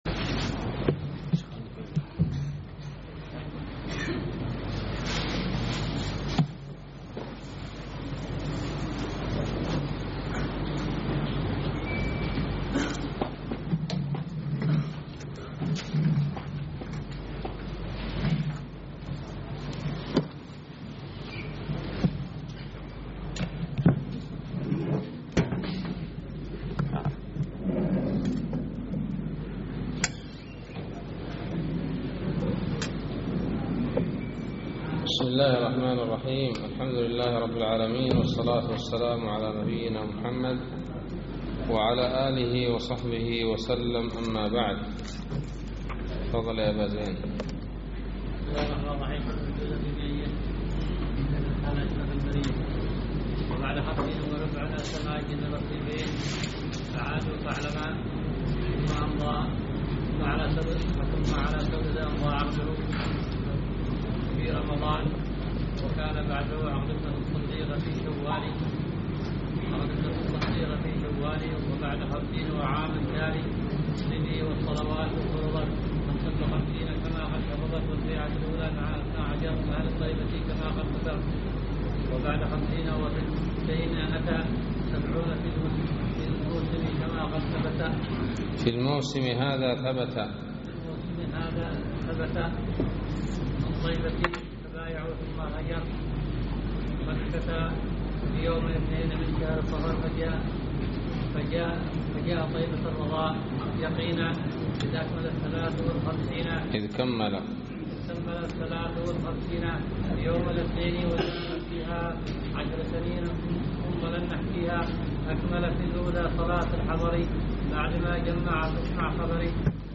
الدرس العشرون من شرح كتاب التوحيد